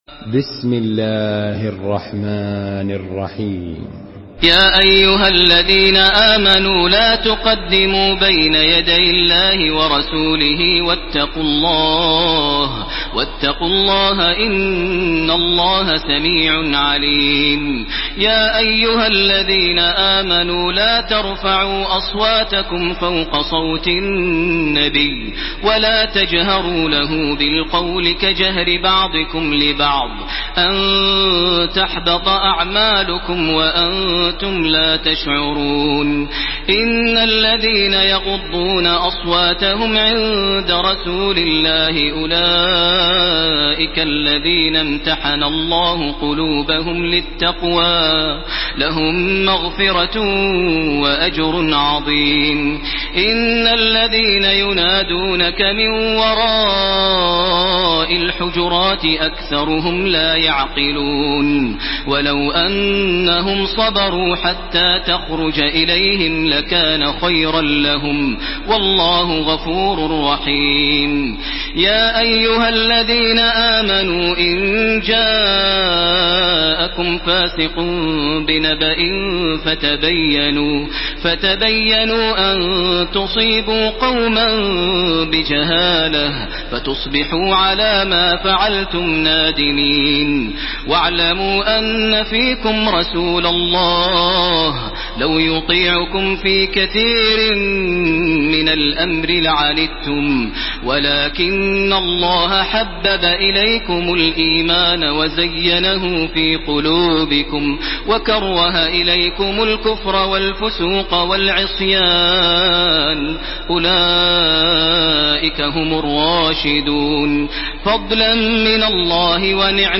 Makkah Taraweeh 1431
Murattal